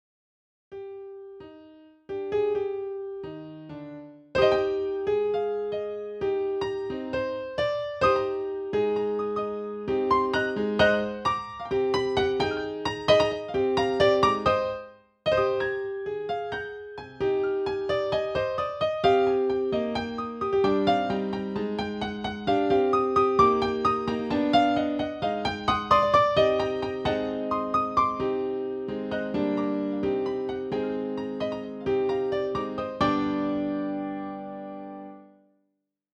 piano solo
Piano